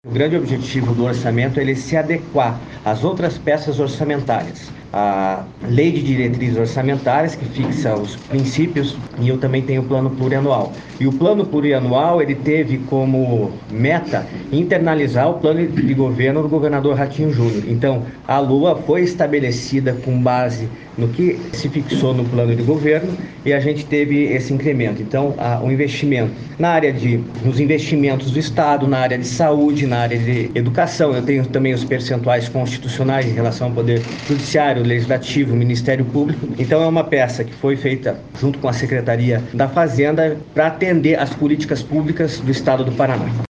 Sonora do chefe da Casa Civil em exercício, Luciano Borges, sobre a entrega da PLOA 2024 à Alep